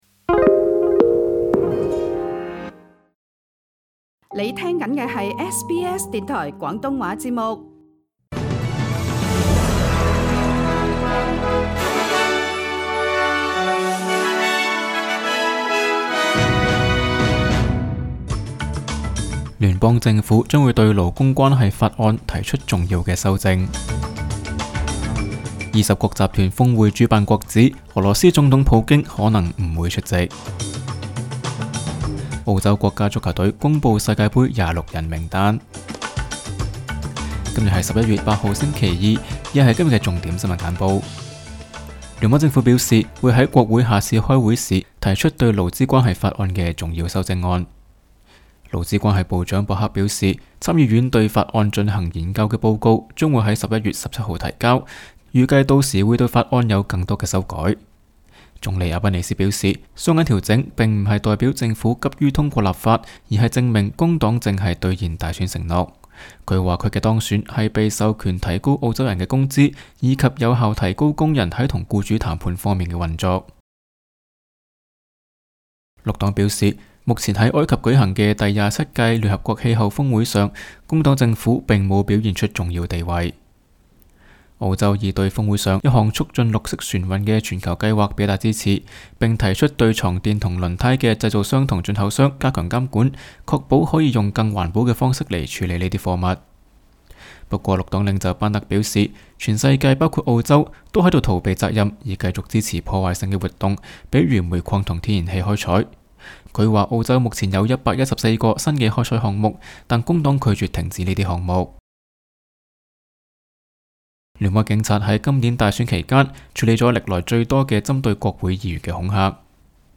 SBS 廣東話節目新聞簡報 Source: SBS / SBS Cantonese